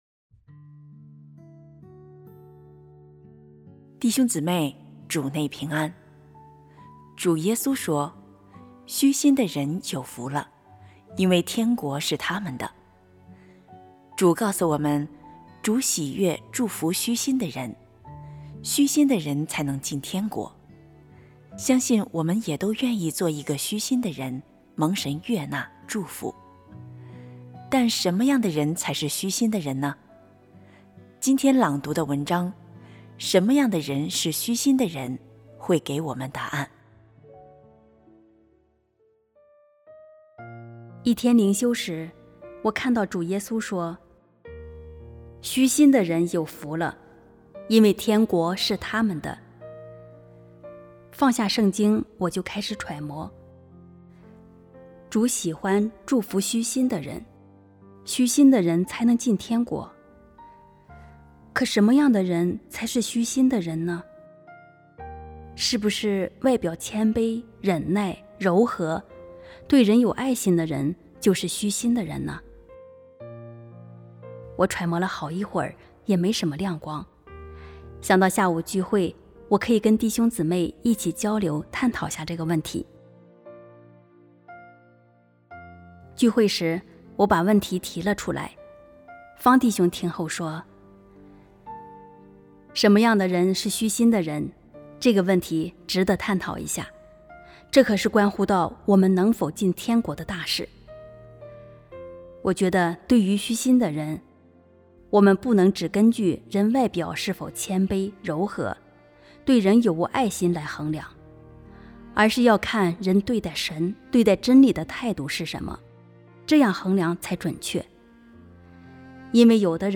什麼樣的人是虛心的人（有聲讀物）